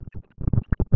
Nota realizada al Pte. Jorge Batlle a la salida del Edificio Plaza Independencia.